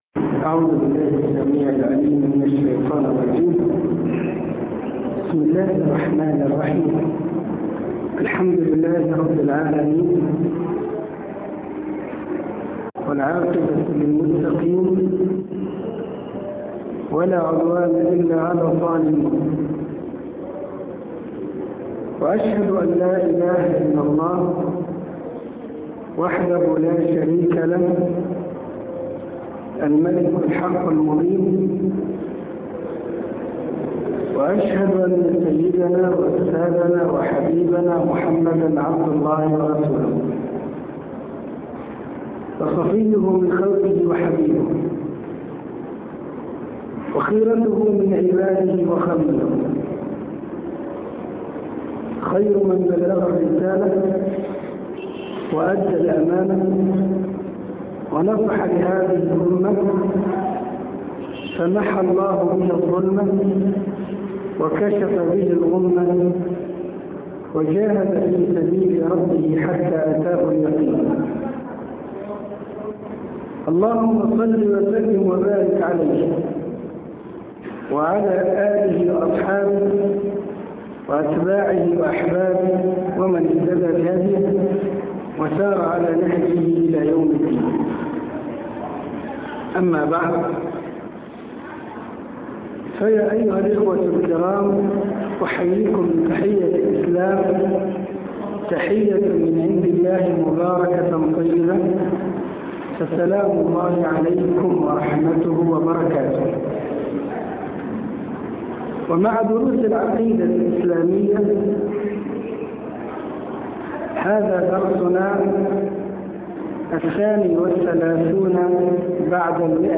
عنوان المادة الدرس(132) نشأة التصوف - شرح العقيده الإسلاميه تاريخ التحميل الخميس 25 فبراير 2021 مـ حجم المادة 27.19 ميجا بايت عدد الزيارات 315 زيارة عدد مرات الحفظ 140 مرة إستماع المادة حفظ المادة اضف تعليقك أرسل لصديق